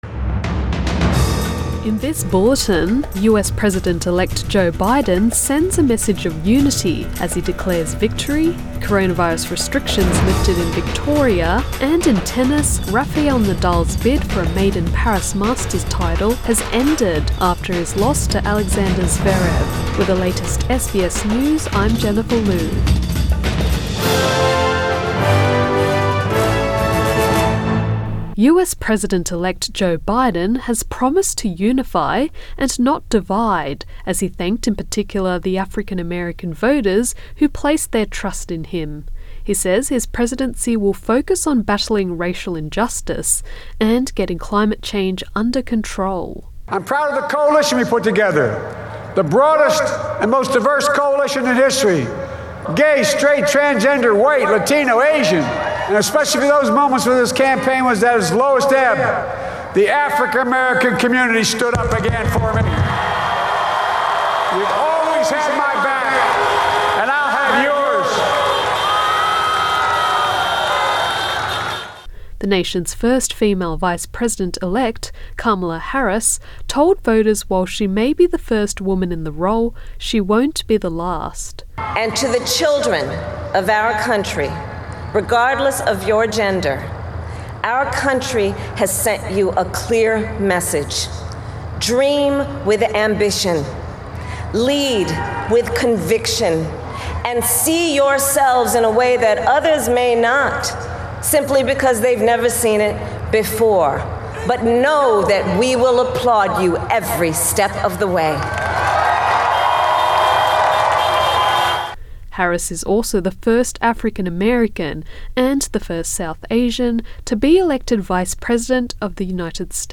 PM bulletin 8 November 2020